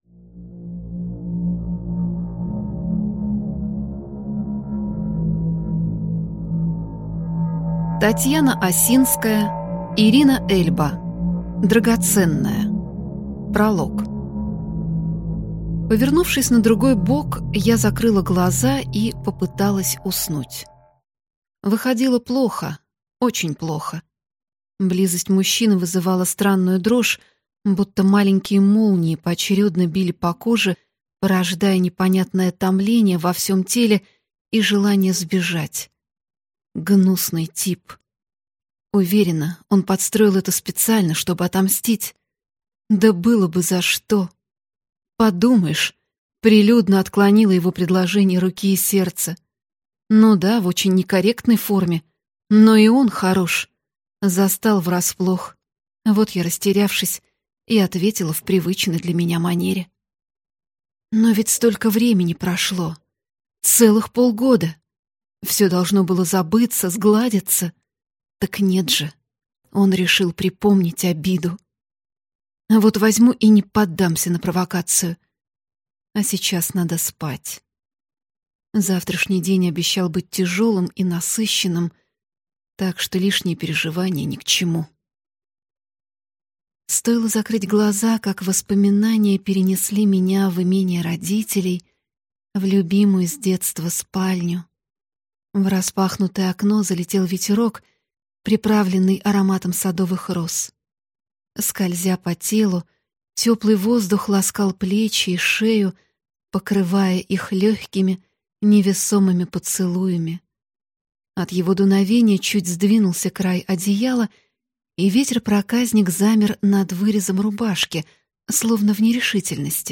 Аудиокнига Драгоценная | Библиотека аудиокниг